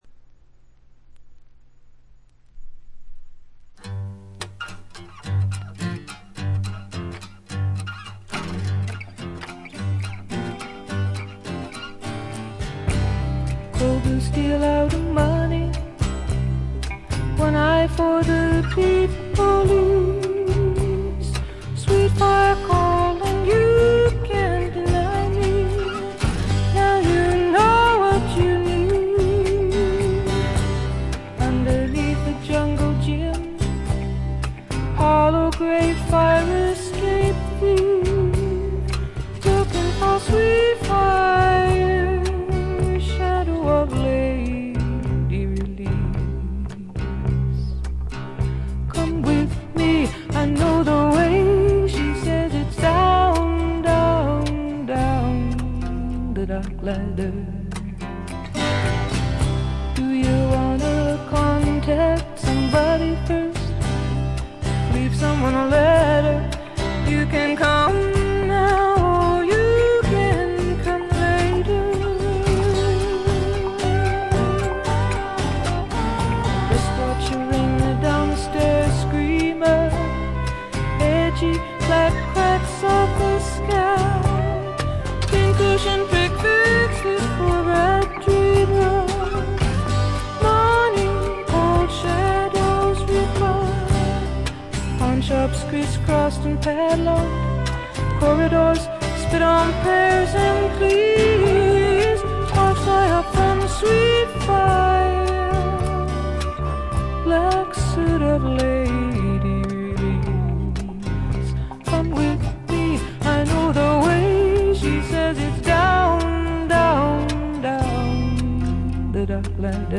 わずかなノイズ感のみ。
試聴曲は現品からの取り込み音源です。
Electric Guitar
Recorded At - A&M Studios